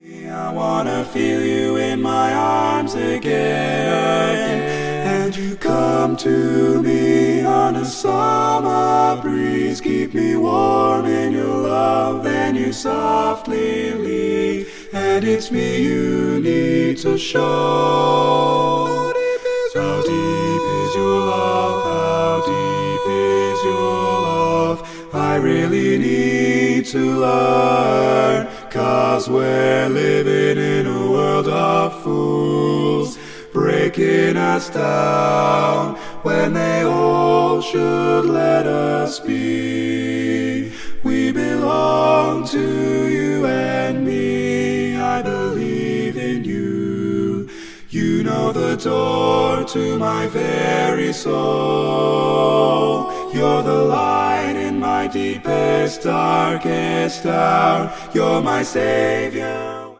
Full mix only
Category: Male